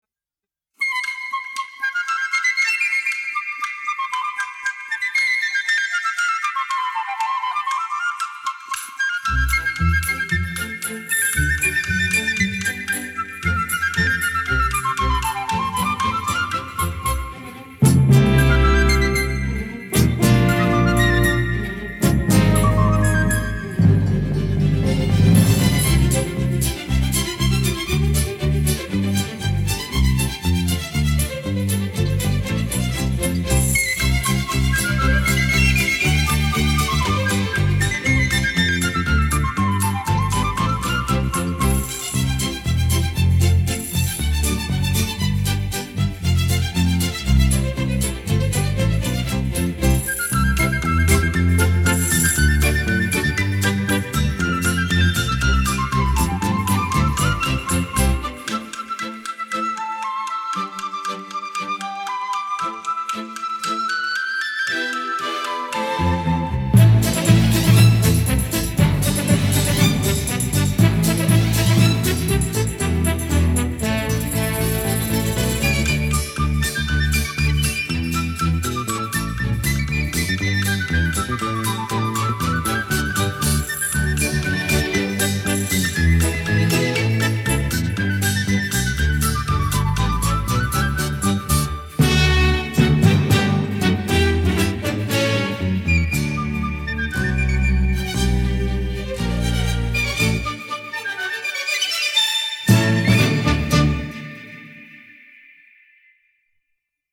[11/12/2009]【欢快的水手角笛舞曲】 sailor's hornpipe